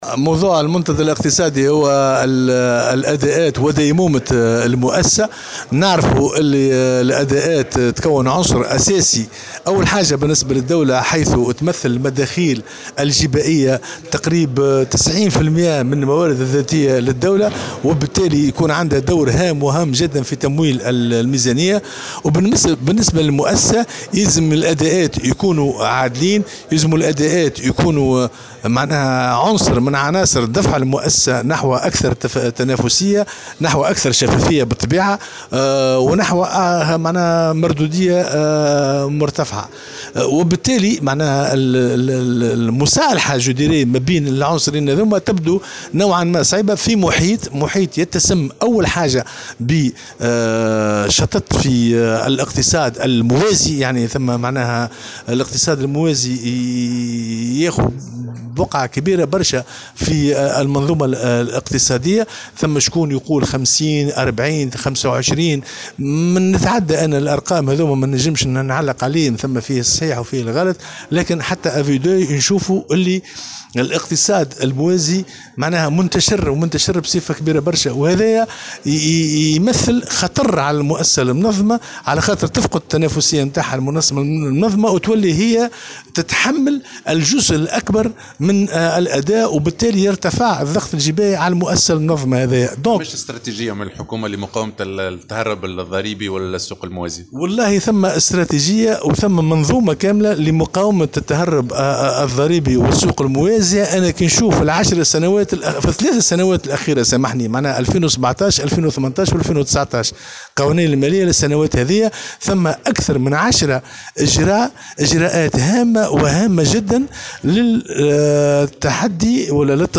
وأوضح في تصريح لمراسل "الجوهرة اف أم" على هامش منتدى حول دور الجباية في ديمومة المؤسسة بصفاقس، ان هذه الحلول تتمثل في تبسيط القوانين الخاصة بالمنظومة الجبائية والاجراءات الجديدة الوراردة بقانون المالية وفي تطبيقها على أرض الواقع بالإضافة إلى دور المراقبة الجبائية، وفق تعبيره، مؤكدا على دورها في مقاومة التهرّب الضريبي.